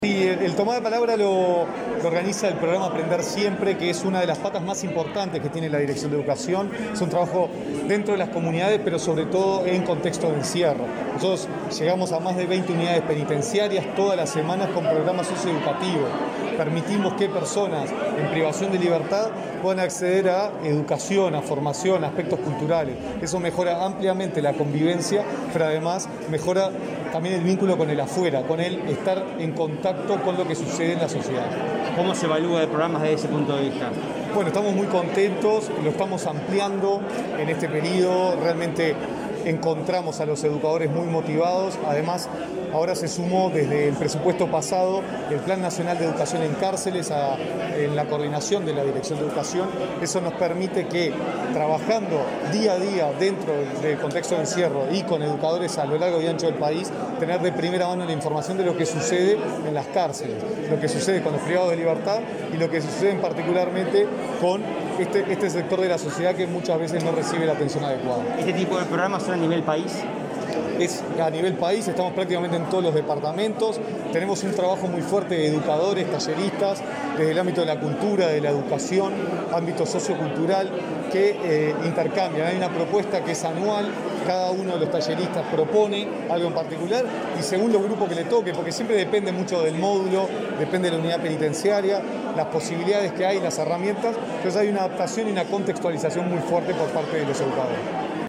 Declaraciones del director de Educación, Gonzalo Baroni
Tras el evento, Baroni efectuó declaraciones a Comunicación Presidencial.